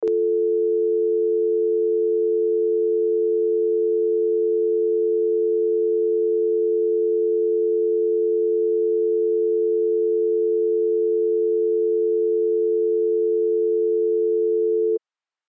جلوه های صوتی
دانلود صدای تلفن 8 از ساعد نیوز با لینک مستقیم و کیفیت بالا
برچسب: دانلود آهنگ های افکت صوتی اشیاء دانلود آلبوم صدای تلفن از افکت صوتی اشیاء